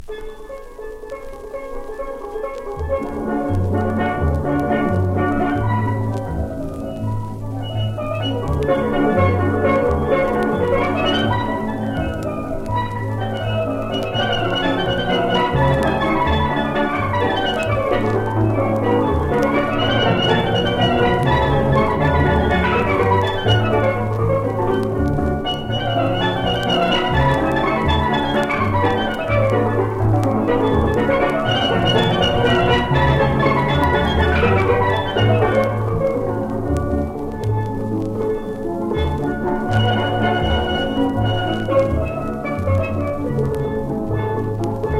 World, Steel band　Trinidad & Tobago　12inchレコード　33rpm　Stereo